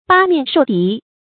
八面受敌 bā miàn shòu dí
八面受敌发音
成语注音ㄅㄚ ㄇㄧㄢˋ ㄕㄡˋ ㄉㄧˊ